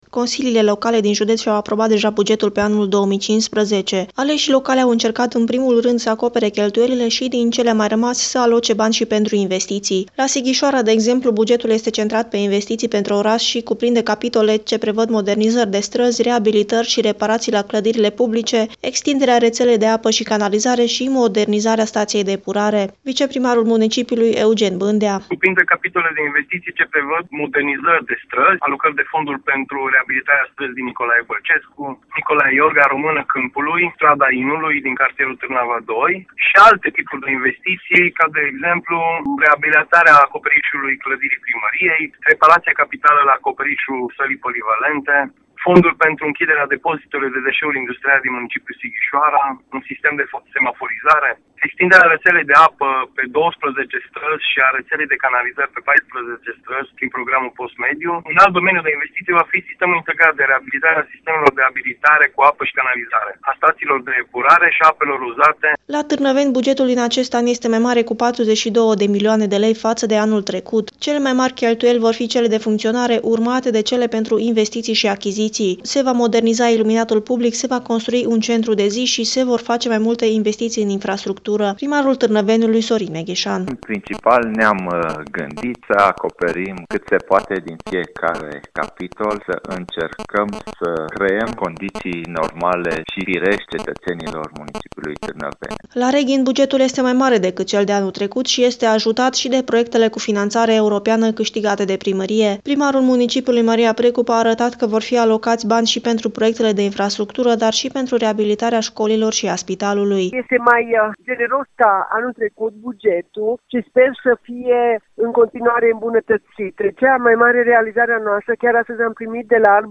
Mai multe detalii, în reportajul